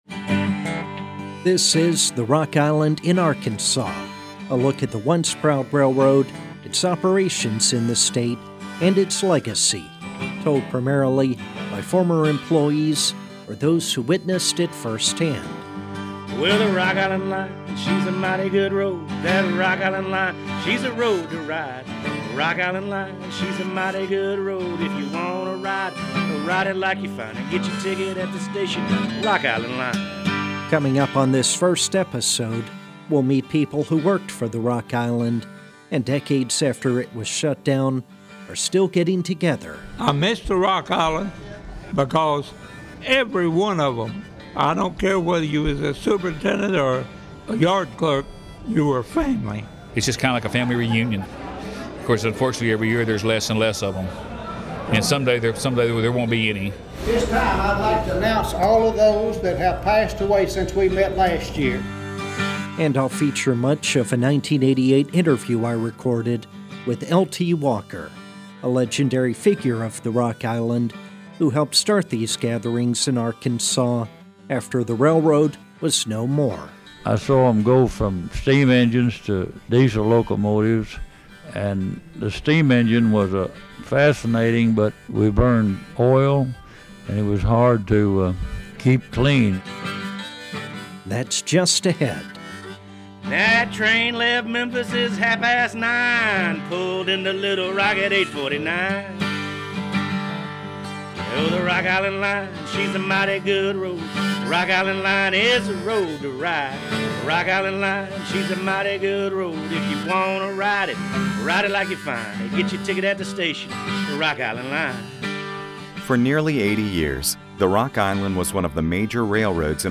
The first episode looks at the regular gatherings of former employees which continue 38 years after the Rock Island was shut down in 1980. In 2016 and 2017 I attended the annual picnics held in Sherwood, Arkansas, setting up a table to record as many stories as possible.